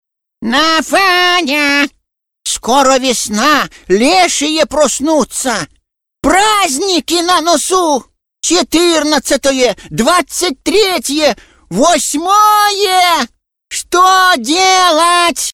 Пародия на голос Домовенка Кузи из одноименного мф-ма
Категория: мужской | средний 30-60
Характеристика: Пародист